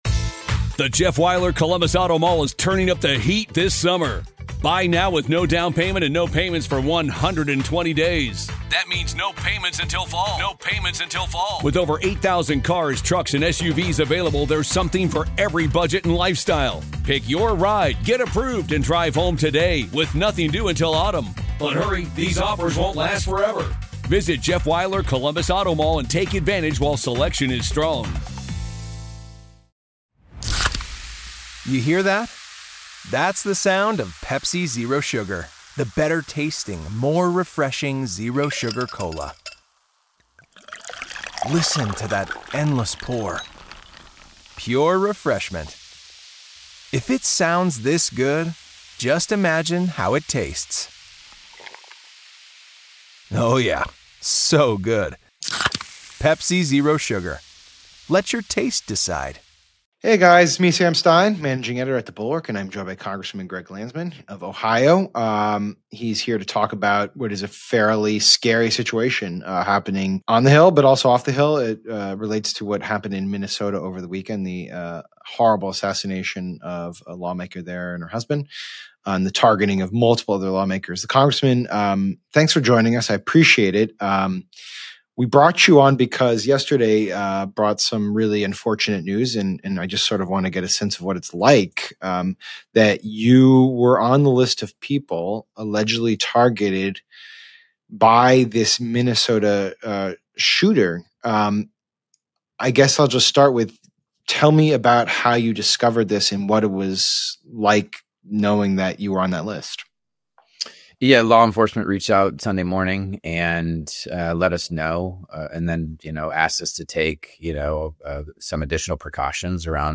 Sam Stein talks with Representative Greg Landsman about being named on a shooter’s target list, what it was like waiting for the suspect to be caught, and why political violence feels closer than ever.